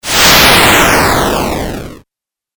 シンセ 8bit 特殊攻撃 ブレス 3
グオァー